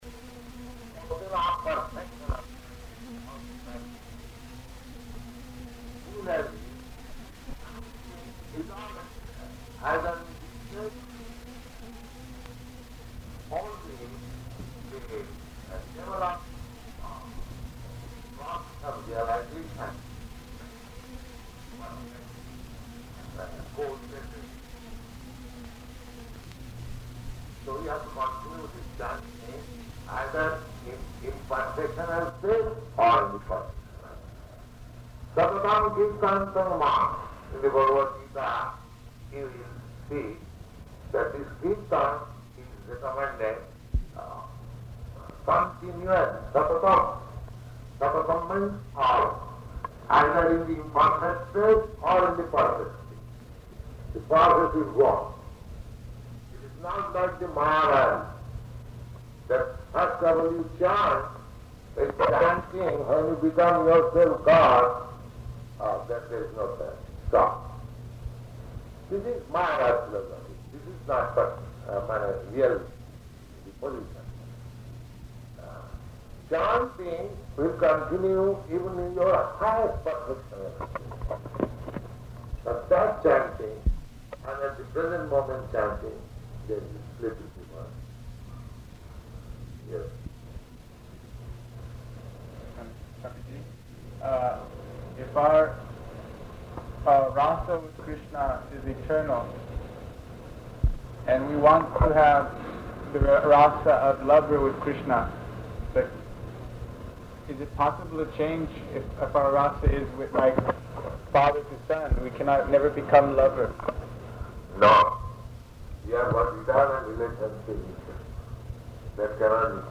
Lecture [partially recorded]
Location: San Francisco
[poor audio]